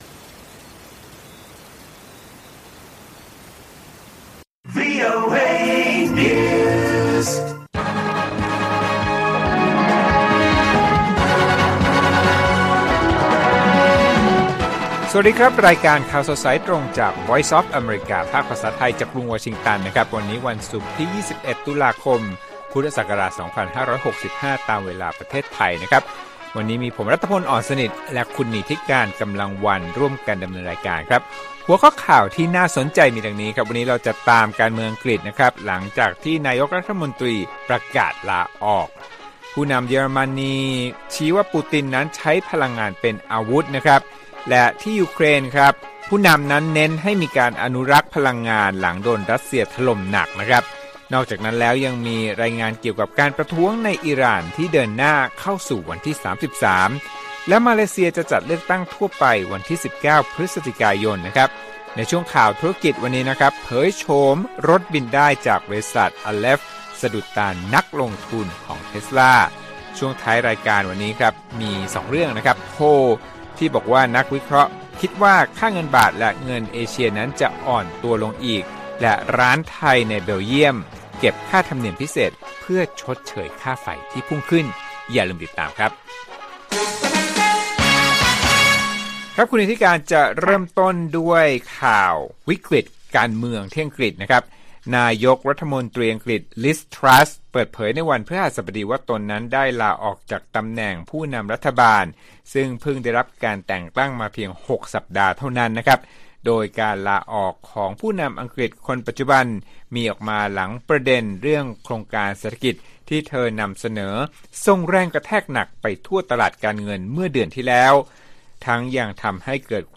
ข่าวสดสายตรงจากวีโอเอไทย ศุกร์ 21 ต.ค. 65